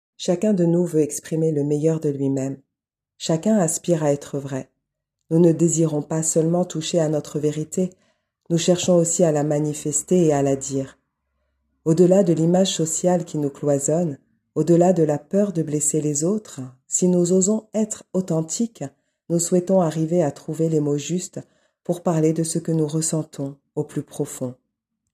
extrait voix off
27 - 62 ans - Mezzo-soprano